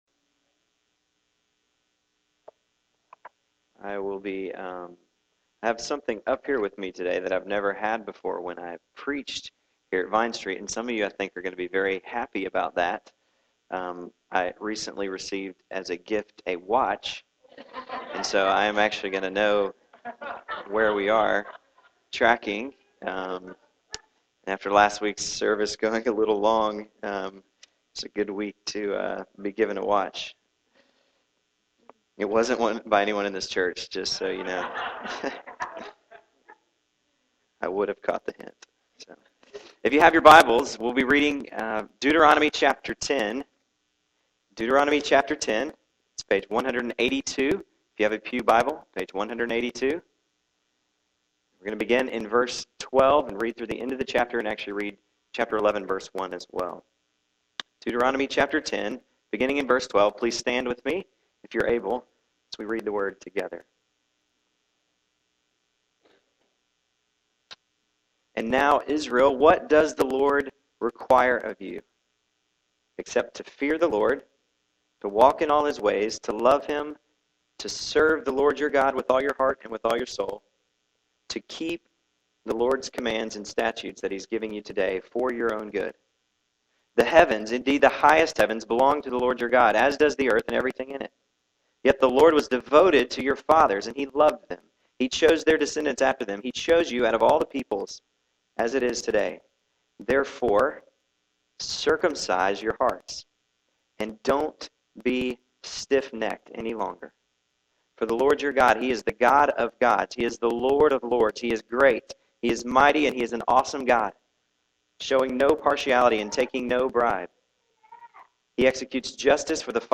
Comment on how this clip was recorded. November 14, 2010 AM Worship | Vine Street Baptist Church